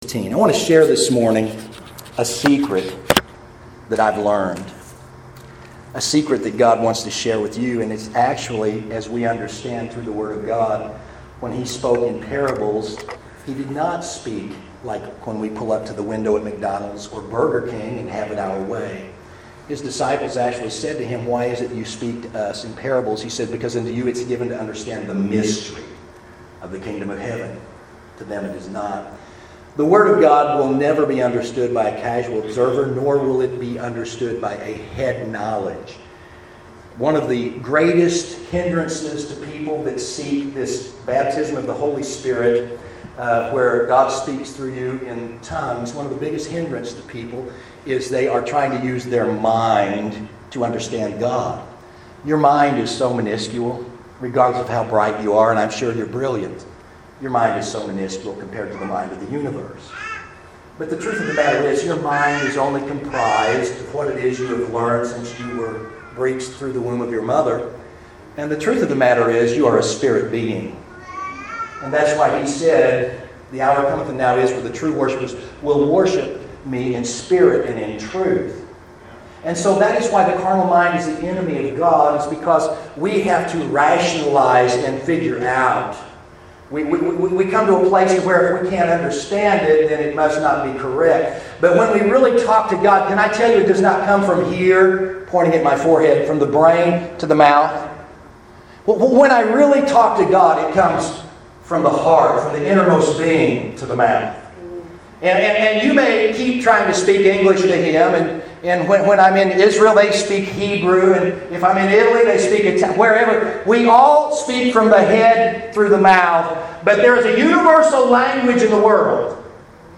There are bulletproof vests for our bodies, and there is an armor for our soul and protection for our spirit. This lively sermon will advise one on how to apply principals that will fully cloth one with spiritual concepts to finish this race.